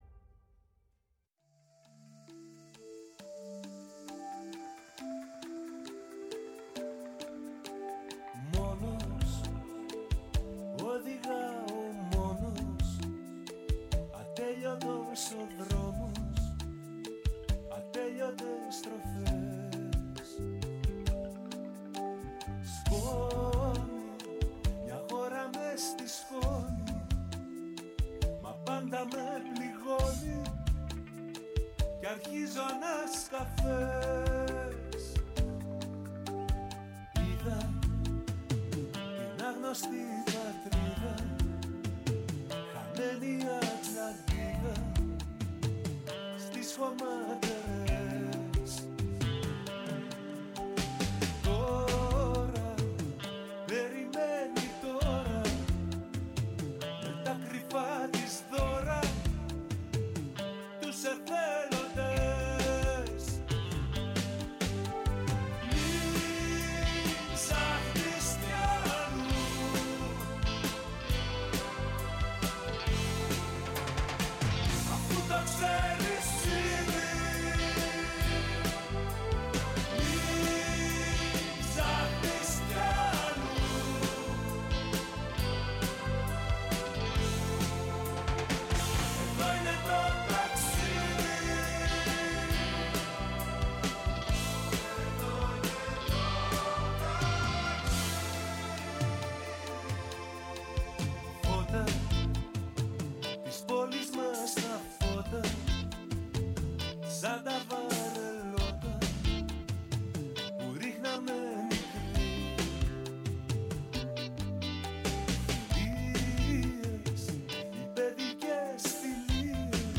Στο στούντιο της “Φωνής της Ελλάδας” φιλοξενήθηκε